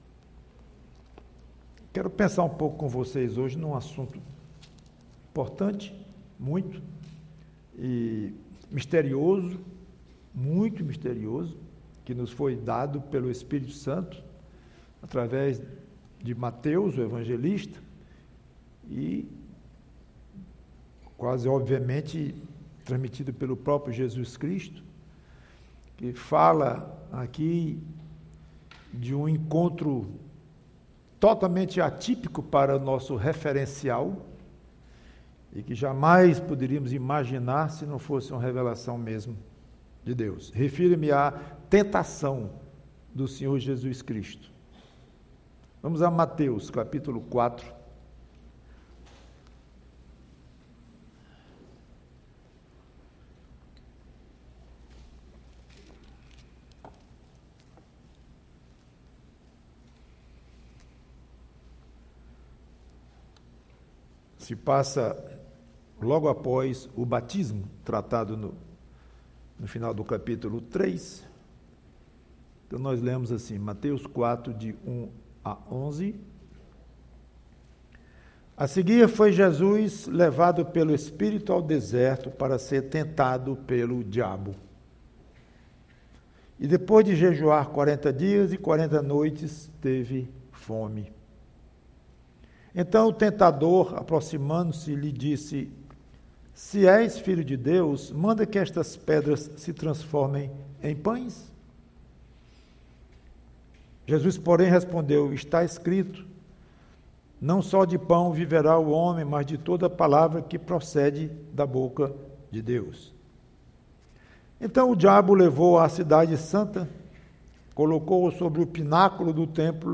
PREGAÇÃO Jesus: tentado e vitorioso!